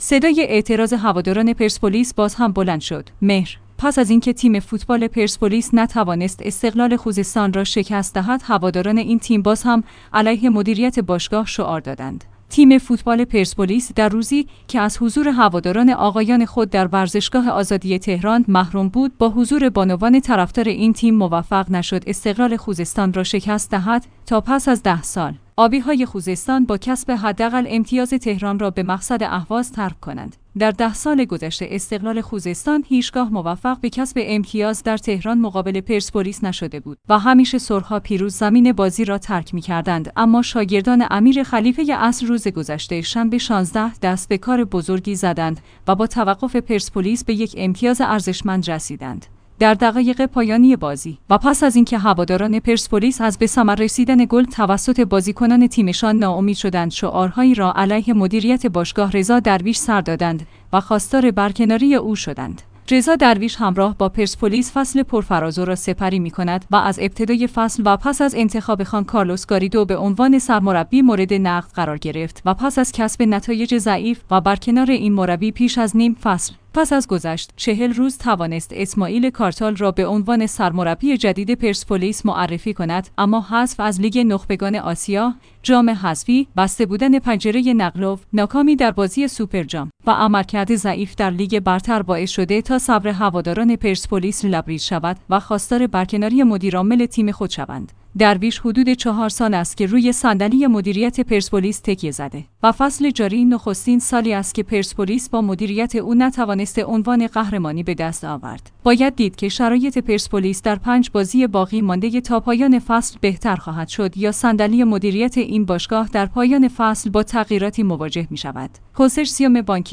صدای اعتراض هواداران پرسپولیس باز هم بلند شد